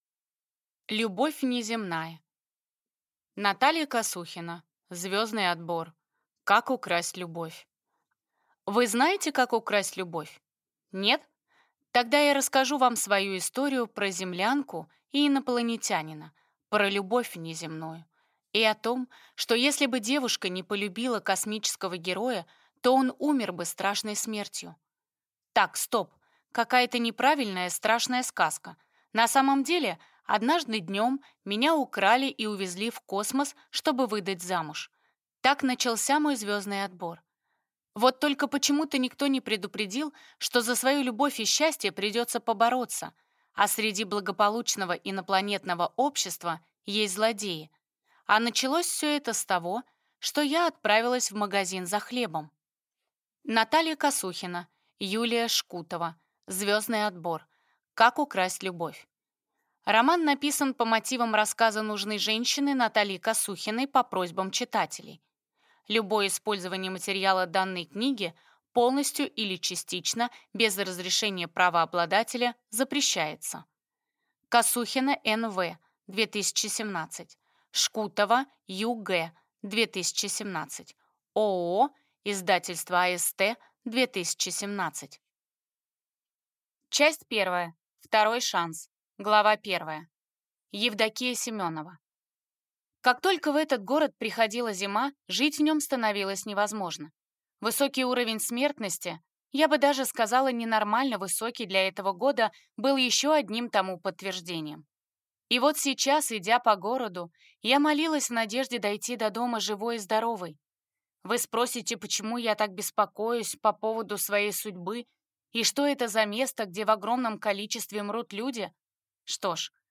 Аудиокнига Звездный отбор. Как украсть любовь | Библиотека аудиокниг